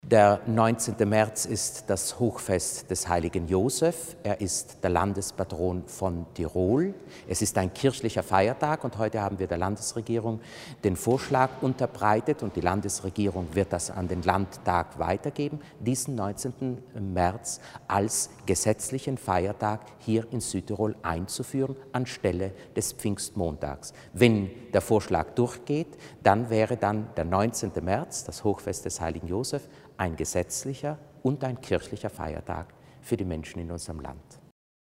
Bischof Muser zur Zusammenarbeit mit der Landesregierung